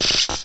cry_not_basculin.aif